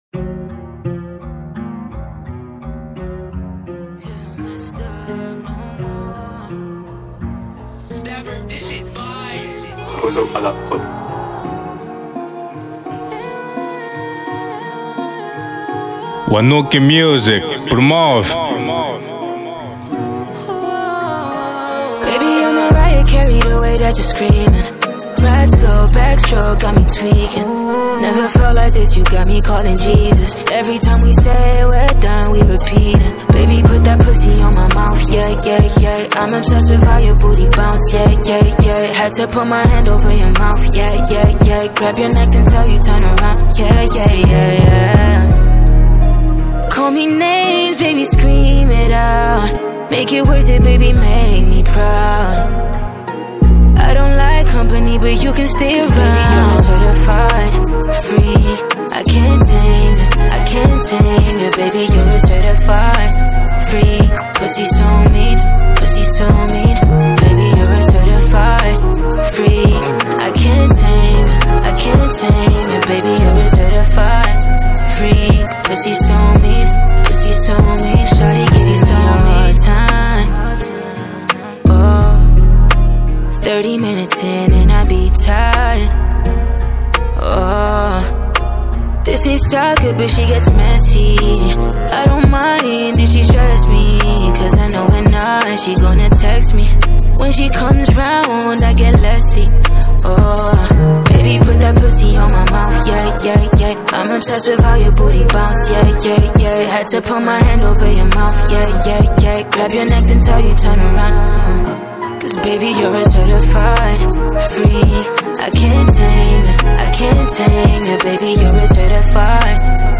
Genero: R&b/soul